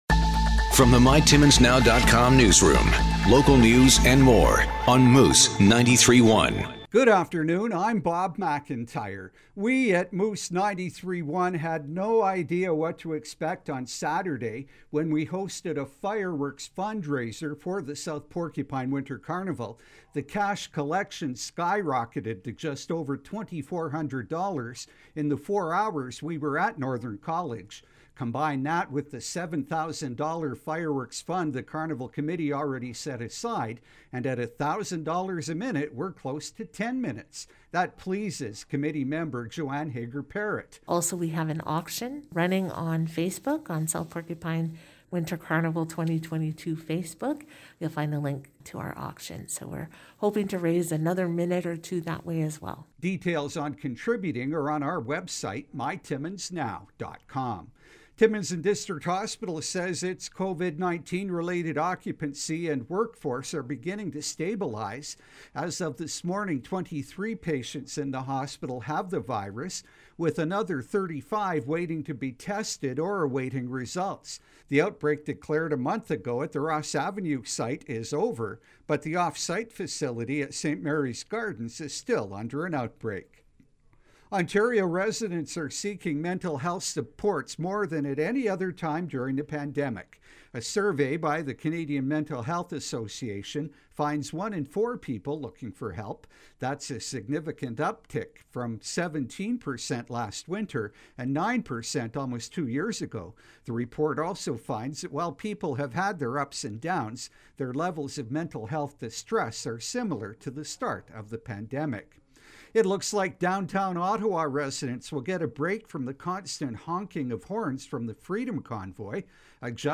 5:00pm Moose News – Mon., Feb. 7, 2022